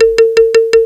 PERC LOOP2-L.wav